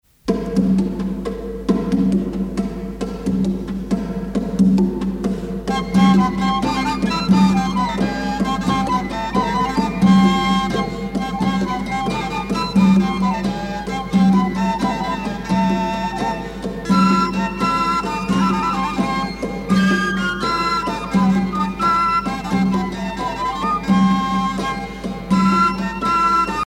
danse : estampie (moyen âge)
Pièce musicale éditée